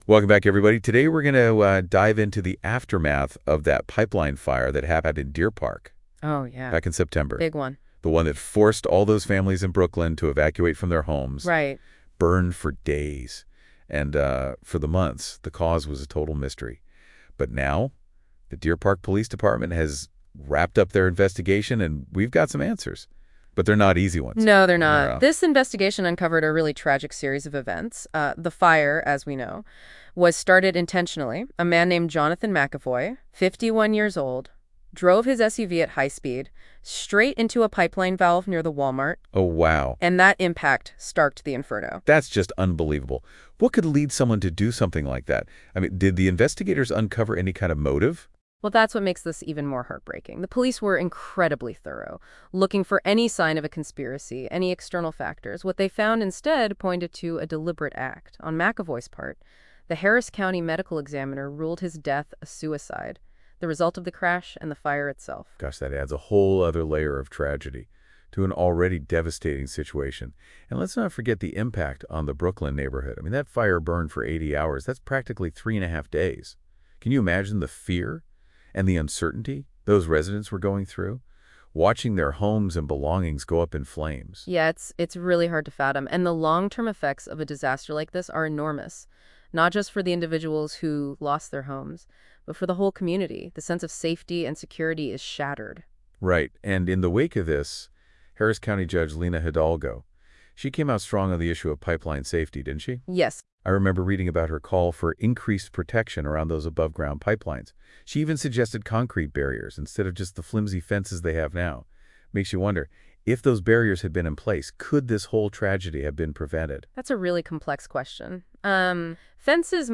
A news report details the conclusion of investigations into a 2024 pipeline fire in Deer Park, Texas.